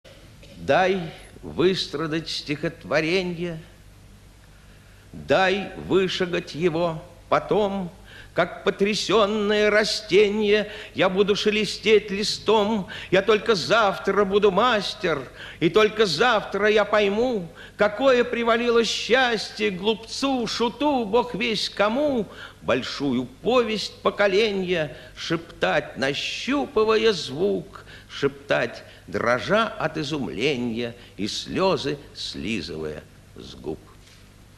2. «Давид Самойлов – Дай выстрадать стихотворенье! (читает автор)» /